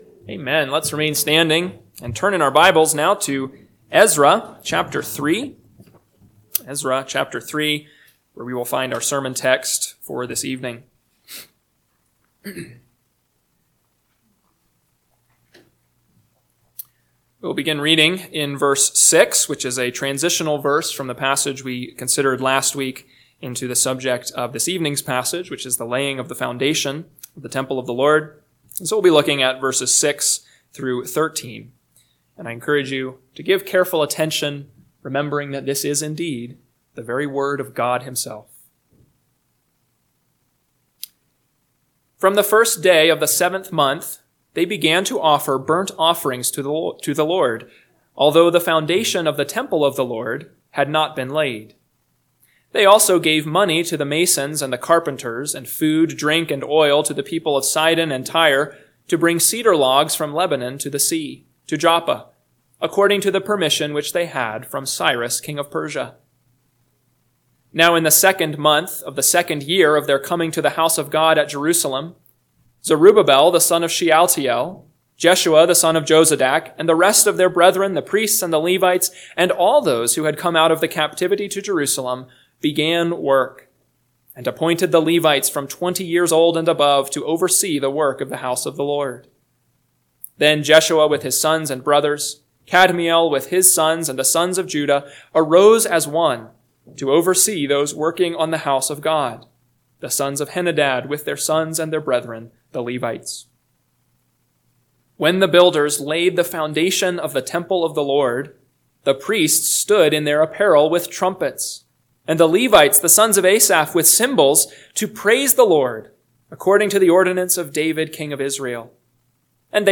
PM Sermon – 3/9/2025 – Ezra 3:6-13 – Northwoods Sermons